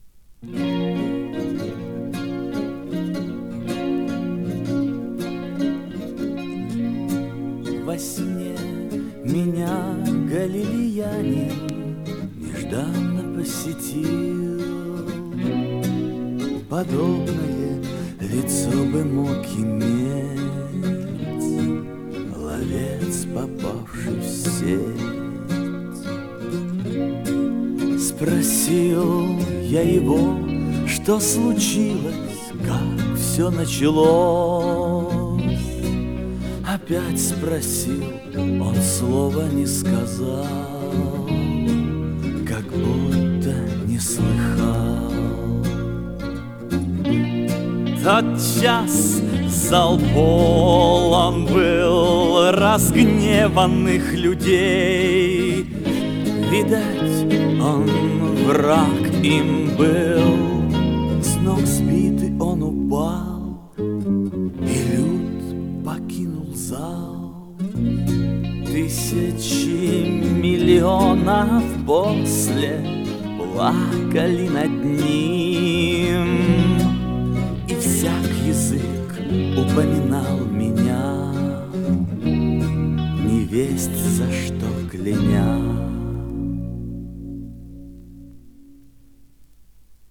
Жанр: Рок-опера
Формат: Vinil, 2 x LP, Stereo, Album
Стиль: Вокал